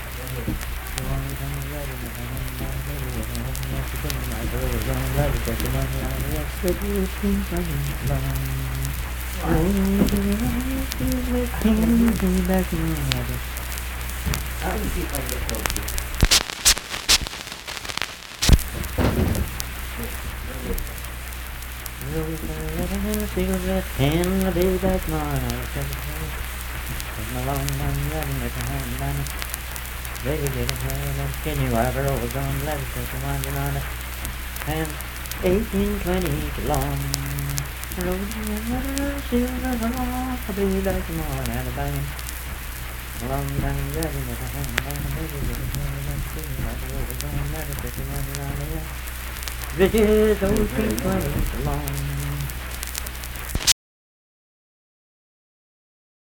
Unaccompanied vocal music
Performed in Dundon, Clay County, WV.
Bawdy Songs
Voice (sung)